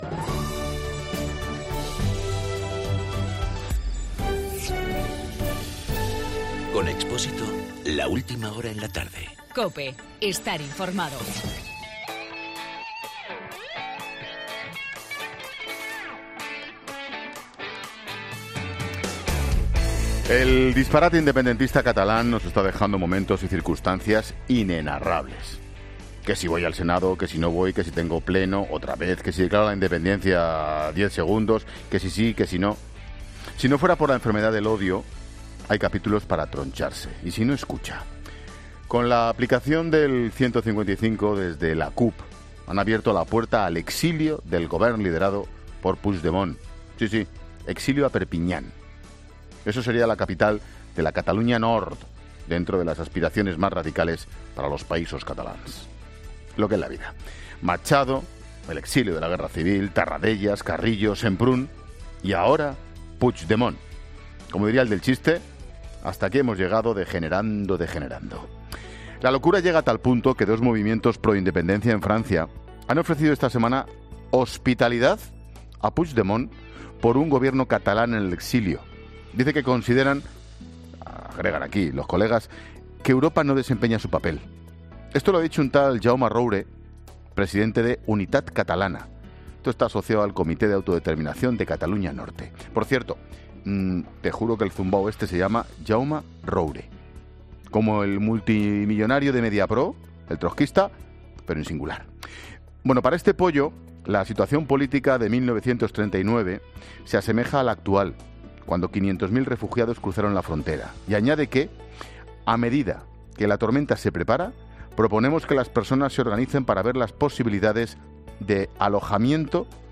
Monólogo de Expósito
Ángel Expósito analiza en su monólogo de las 16 horas la situación de la crisis catalana.